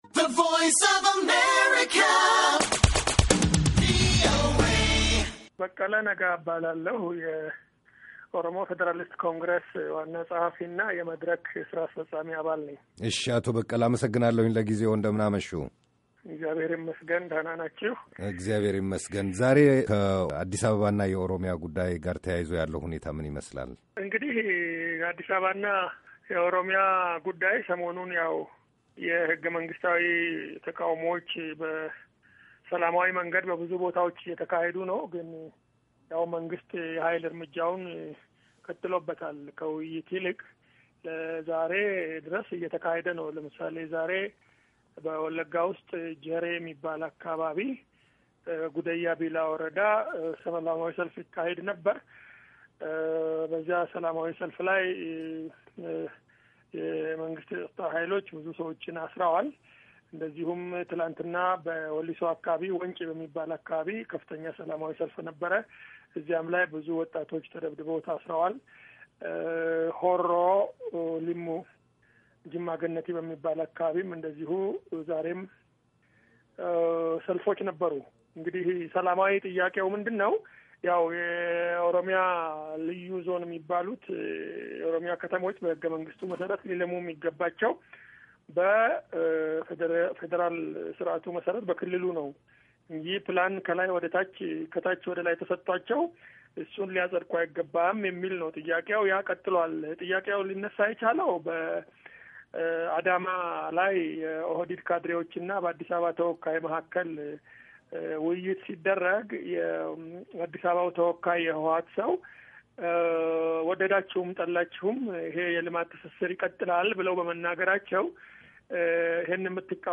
VOA, Interview